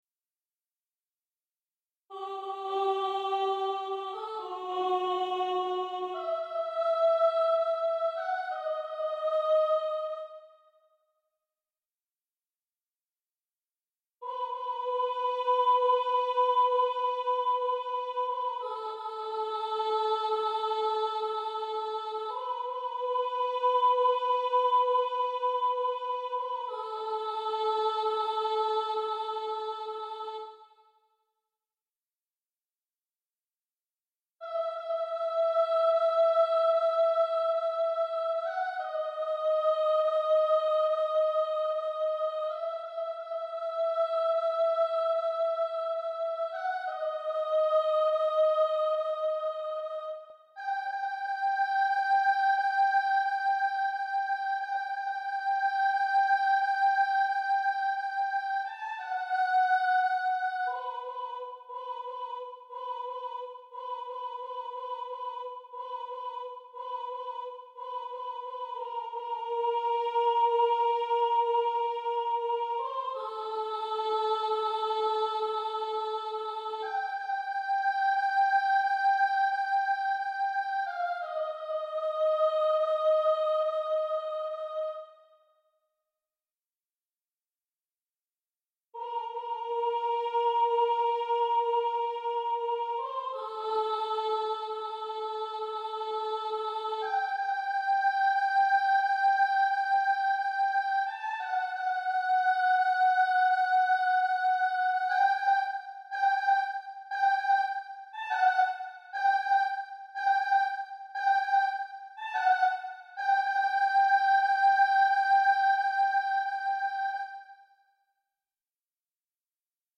Genre: Musique de film
Choeur 3 voix SAT